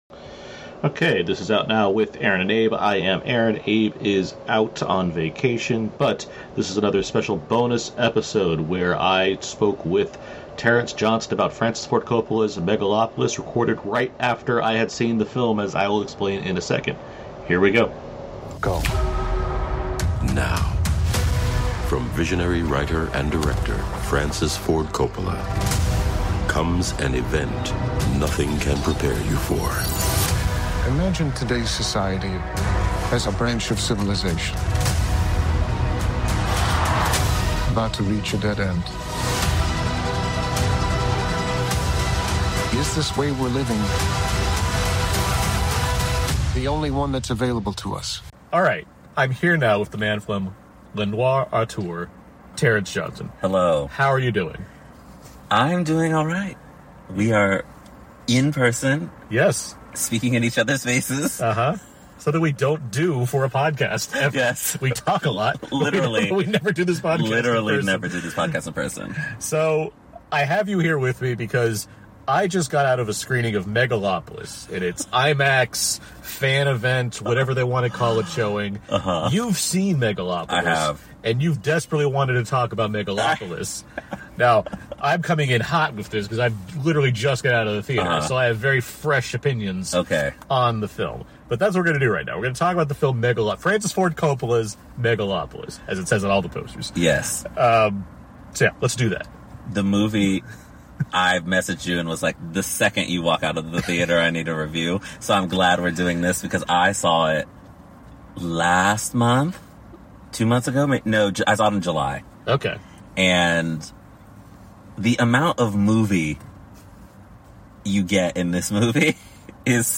Recorded in-person, hear what the two have to say about the film directly after the first general audience showing, including thoughts on the cast, the themes in mind, and what it means to invest so much of oneself into a massive feature film.